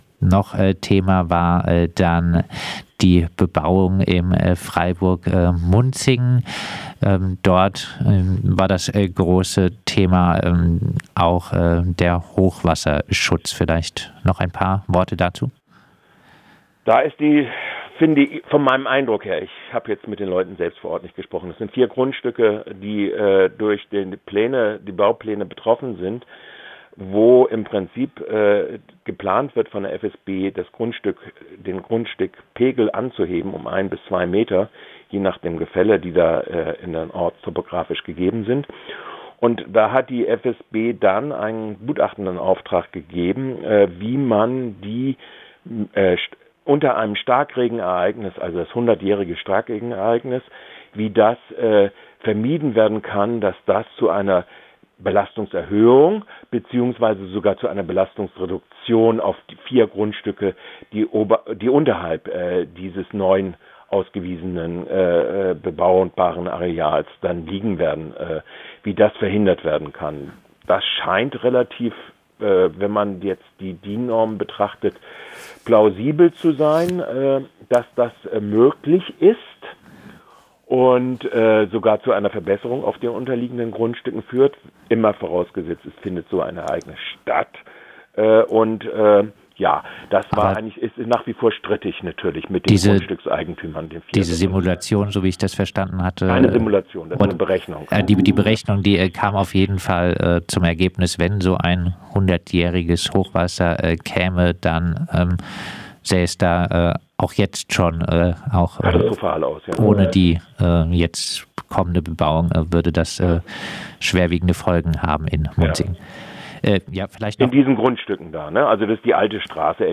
Kollegengespräch zur Bebauung in Munzingen: